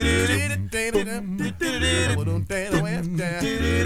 ACCAPELLA 8B.wav